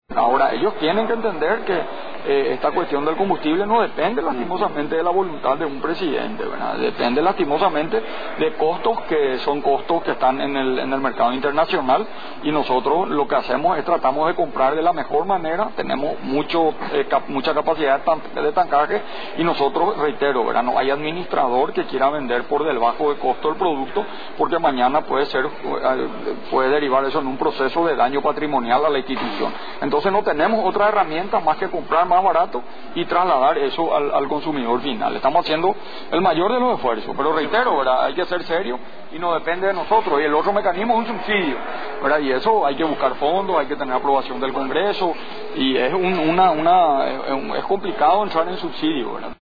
Asunción, Radio Nacional.-El Presidente de la República, Mario Abdo, afirmó este jueves durante la jornada de Gobierno realizado en el departamento de Itapúa, que en 15 días más podrían descender los precios de los hidrocarburos y que con las compras nuevas que efectúen beneficiarán a la ciudadanía.